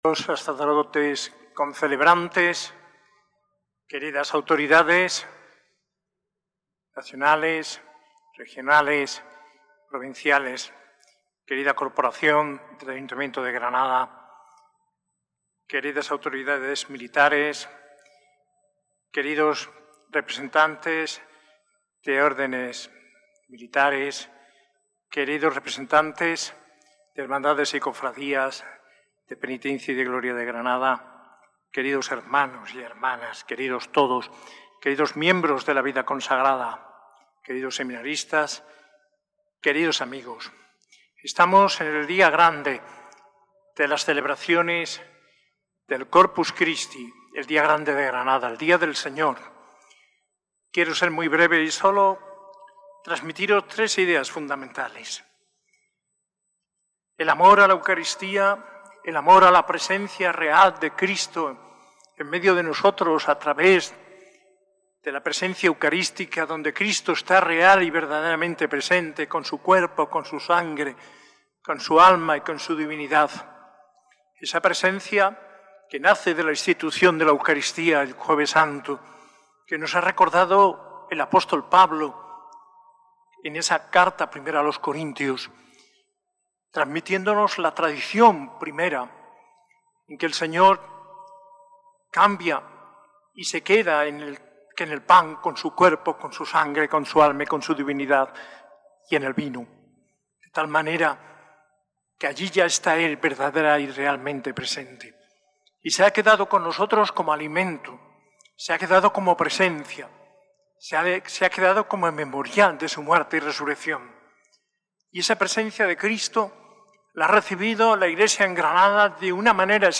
Homilía en el jueves de Corpus Christi de manos del arzobispo Mons. José María Gil Tamayo, celebrada en la S.A.I Catedral el 19 de junio de 2025.